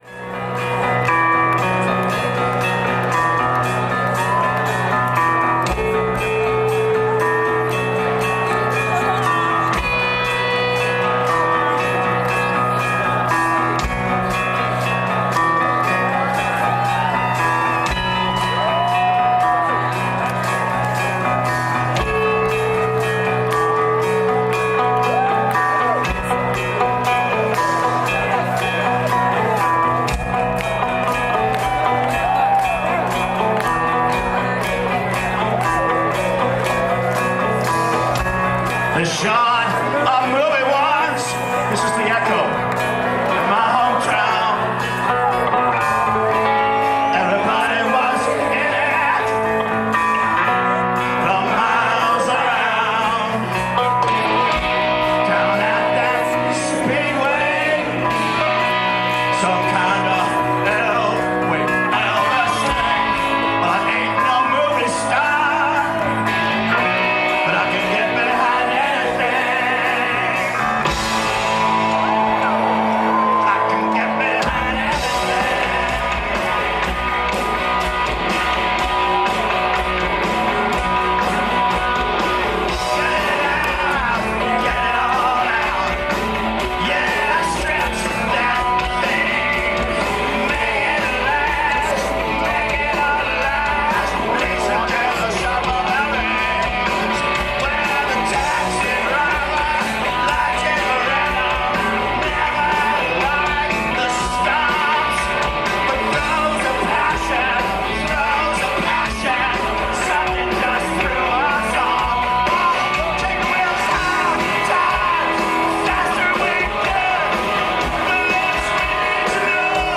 Live In 2011